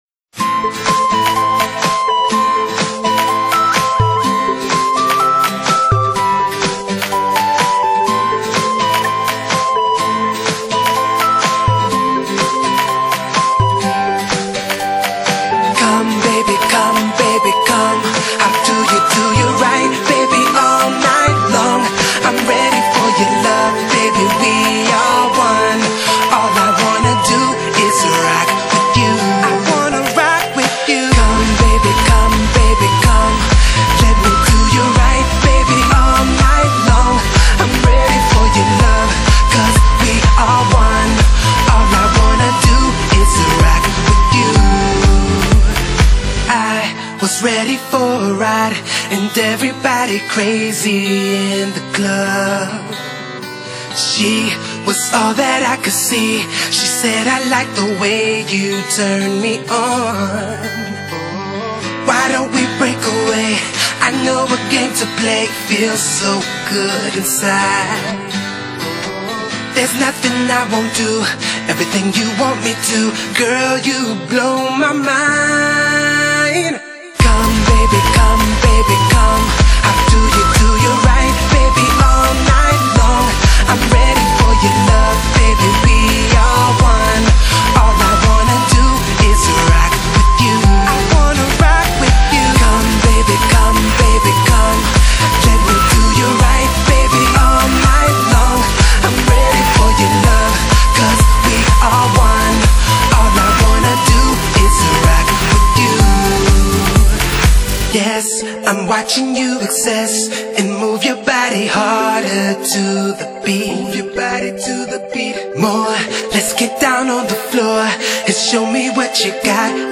Genre: Dance, Pop | 22 Tracks | (百度盤)=215.6M